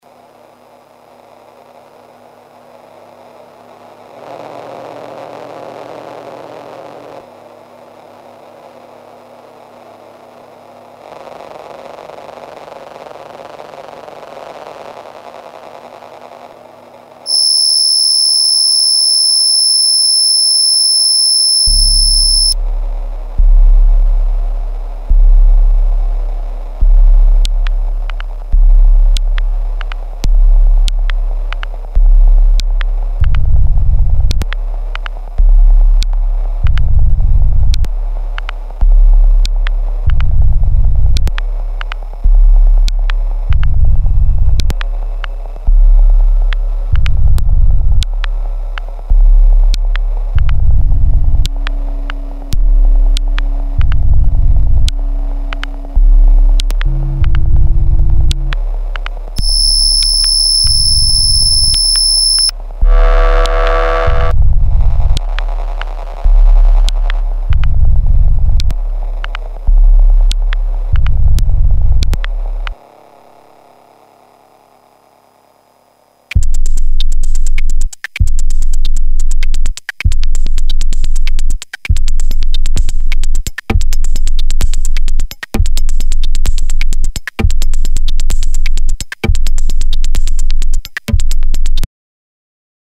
These are forensic, high-intensity impacts designed to inhabit the absolute extremes of the frequency spectrum.
• The Zero-FX Rule: None of these patches use Reverb or Delay. They are bone-dry, surgical, and raw. They rely on the Digitone’s FM engine to provide the air and the grit.
Suitable for Minimal Techno, Noise, IDM, Glitch, Drone and more.